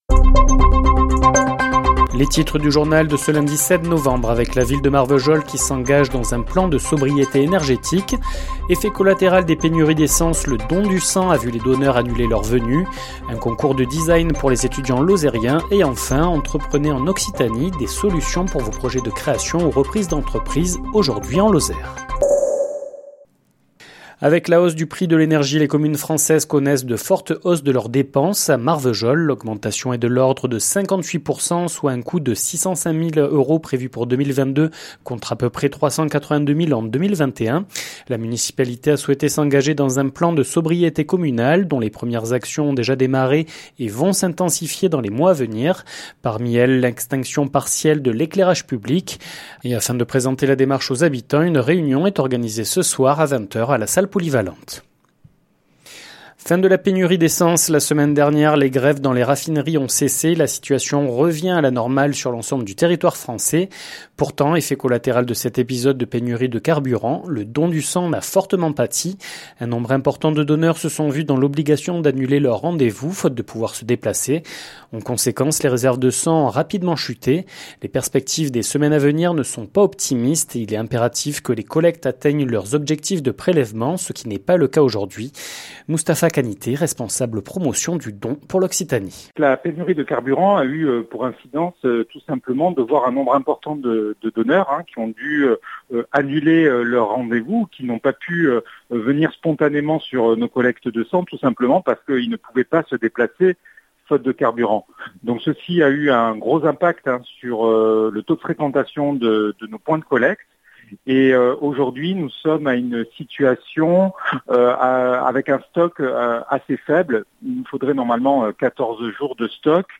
Les informations locales
Le journal sur 48FM présenté par